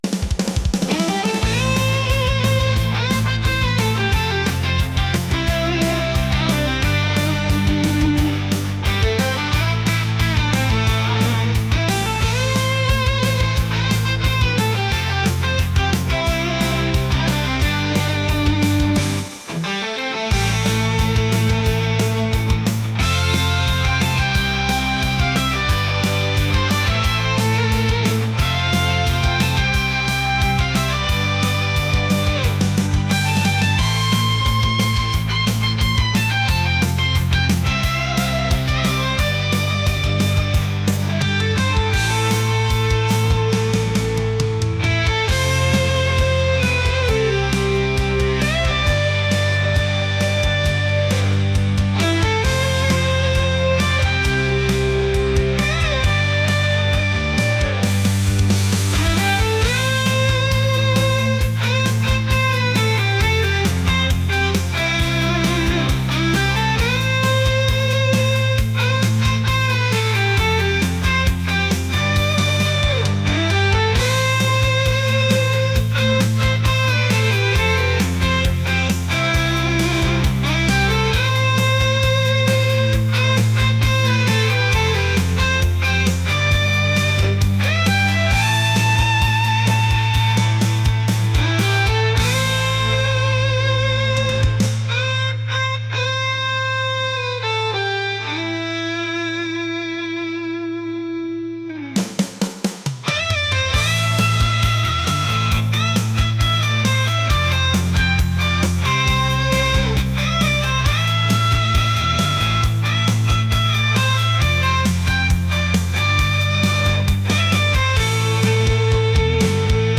energetic | rock